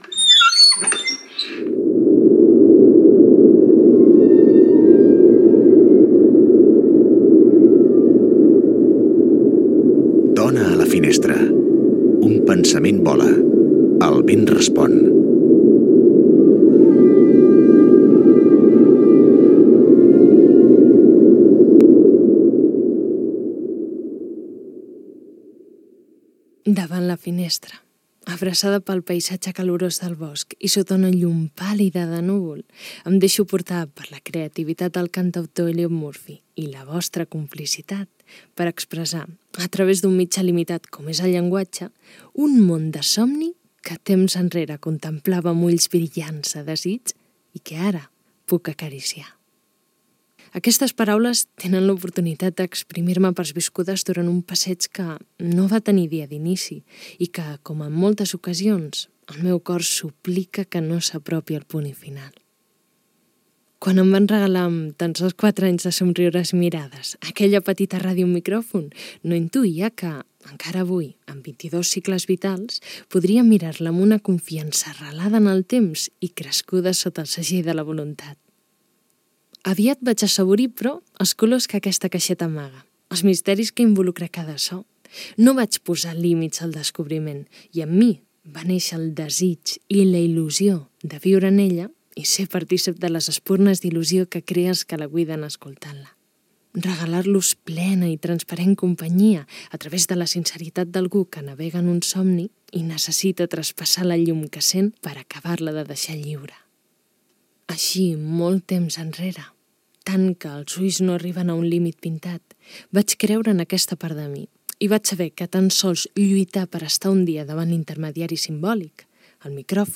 Careta del programa i lectura dels pensaments d'una dona sobre la ràdio
Entreteniment